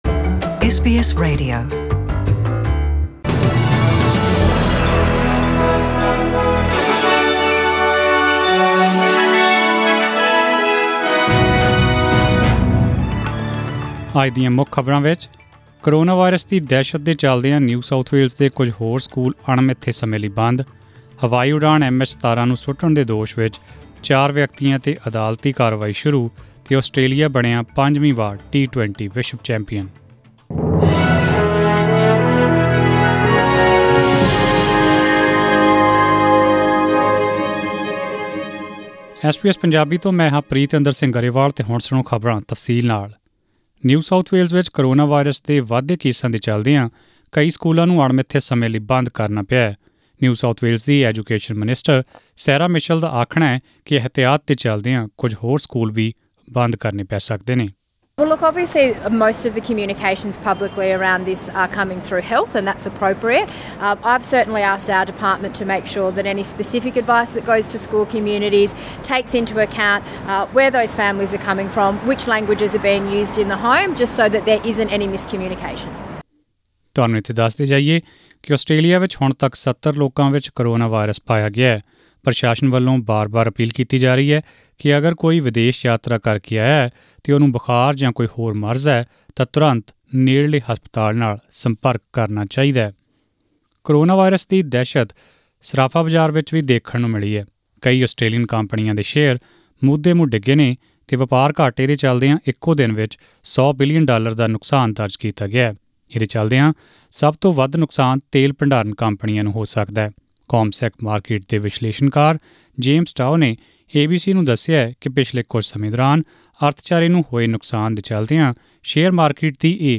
In this bulletin,